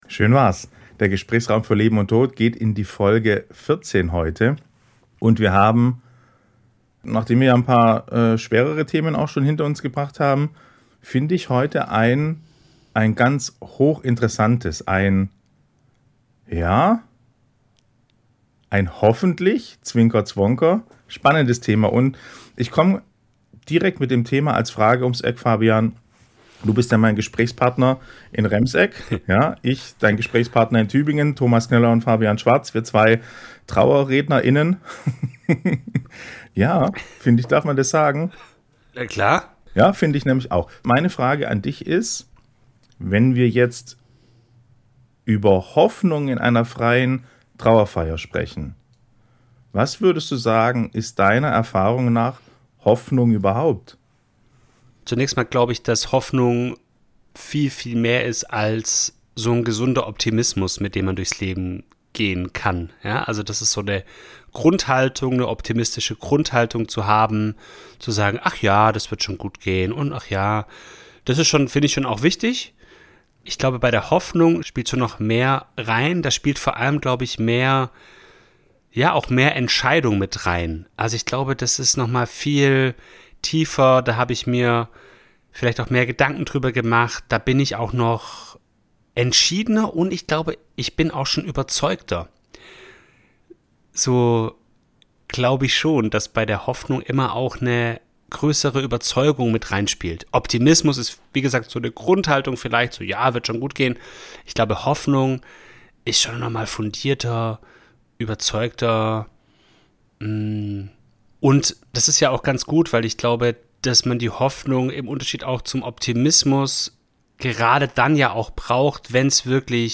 Wir teilen Gedanken aus unserer Arbeit als Trauerredner und sprechen auch persönlich darüber, was für uns Hoffnung bedeutet.